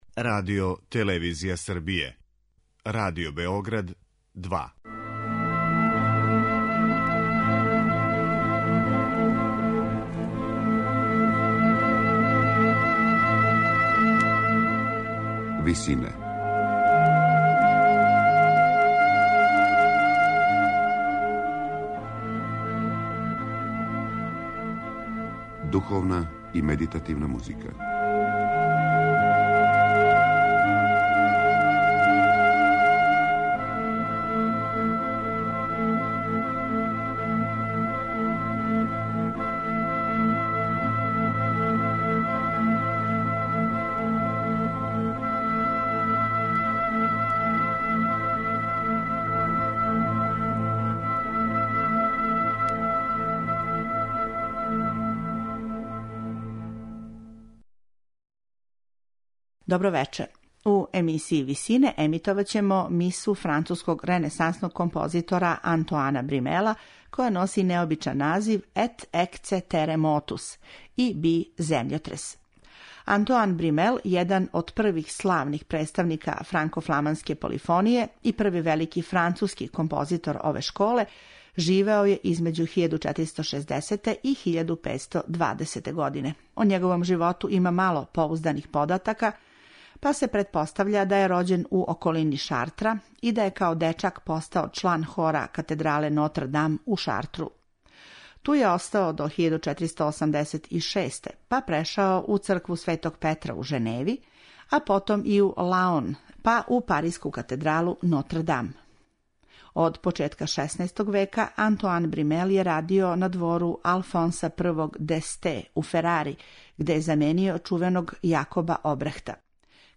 У емисији "Висине" емитоваћемо мису француског ренесансног композитора Антоана Бримела која носи необичан назив "И би земљотрес".
а изводи га вокално-инструментални ансамбл "Клемен Жанекен".